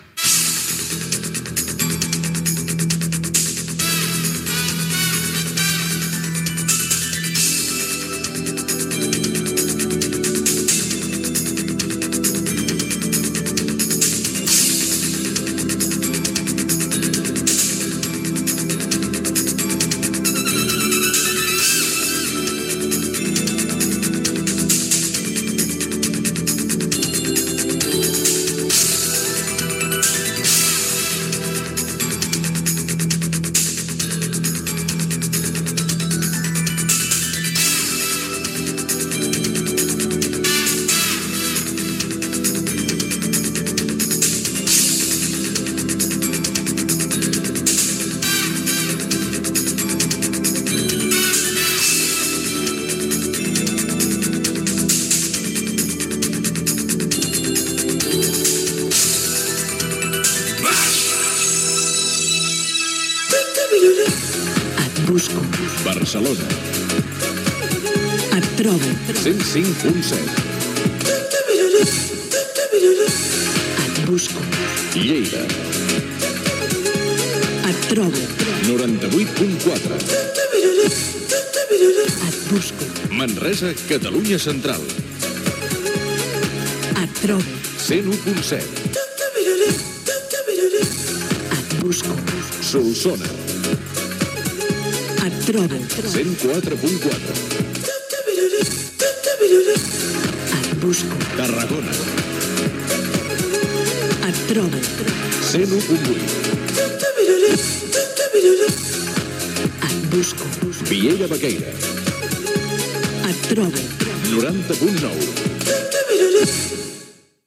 Indicatiu de la ràdio i freqüències a Barcelona, Lleida, Manresa, Solsona, Tarragona i Vielha Baquèria